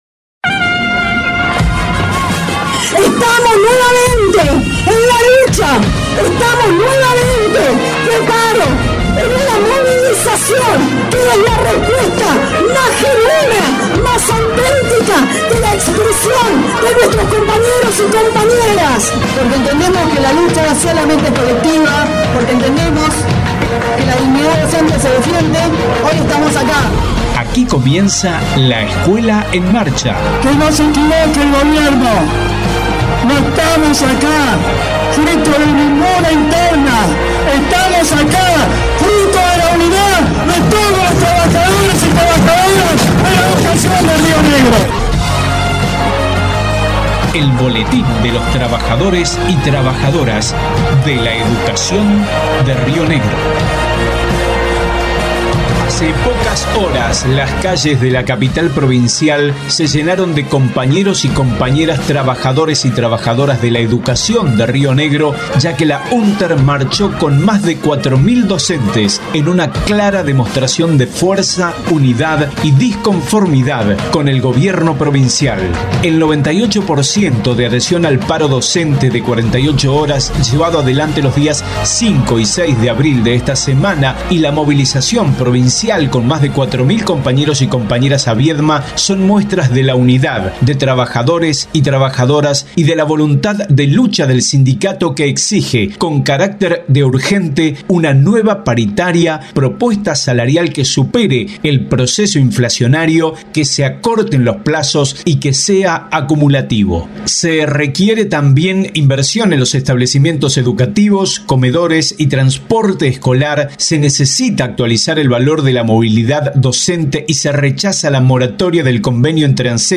Audios de intervenciones en acto de cierre marcha provincial en Viedma el 6/04/22